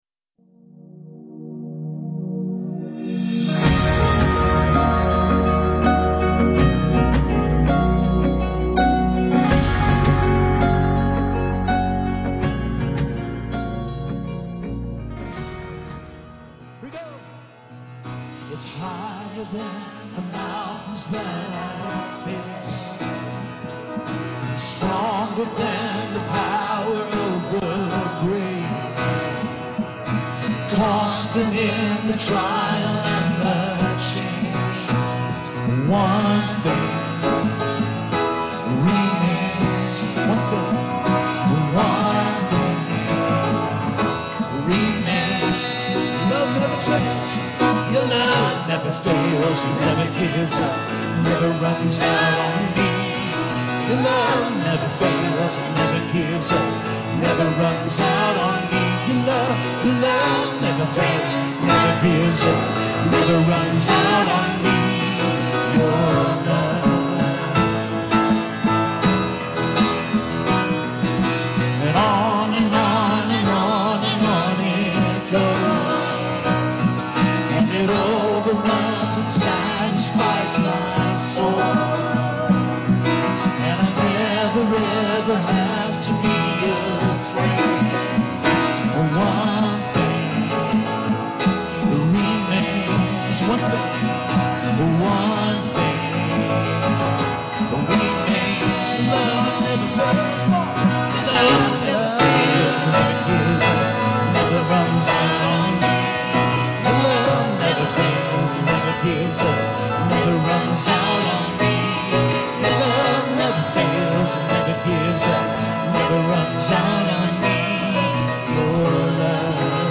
Sermon on Colossians 3 about navigating conflict, forgiveness, and emotional maturity in Christian community, learning to bear with and love one another in unity.